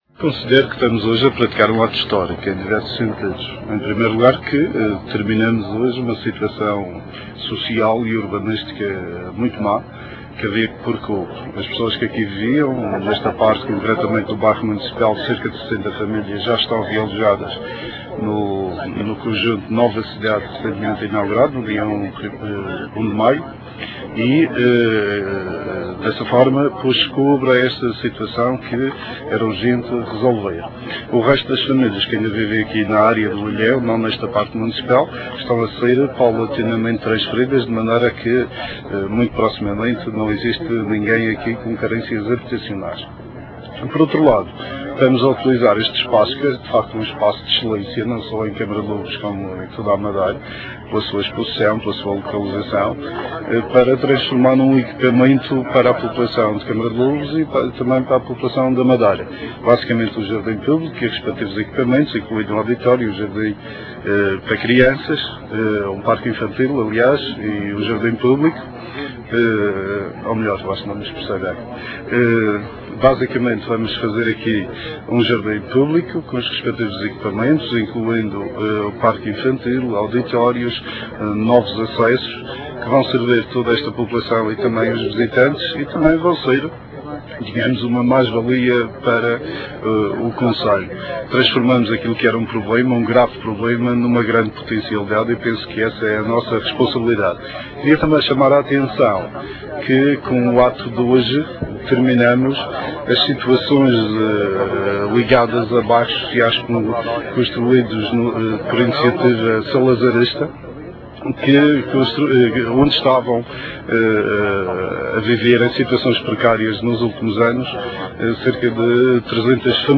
Palavras do Secretário Regional do Ambiente e Recursos Naturais, Dr. Manuel António, proferidas por ocasião do acto solene de demolição do Bairro do Ilhéu de Câmara de Lobos, no dia 22 de Junho de 2004.
bairro_ilheu_destruicao_palavras_manuel_antonio.wma